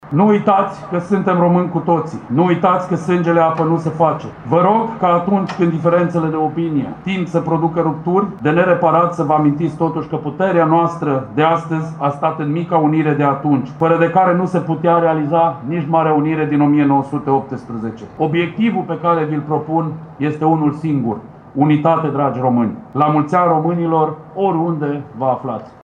La Timișoara, manifestările s-au desfășurat în fața bustului domnitorului Alexandru Ioan Cuza, așezat în curtea Ispectoratului Județean de Poliție Timiș.
La ceremonia militară și religioasă au asistat câteva zeci de persoane, cei mai mulți fiind tineri.
După ce a rememorat momentul istoric de la 1859, viceprimarul Timișoarei, Cosmin Tabără, a făcut un apel la unitate: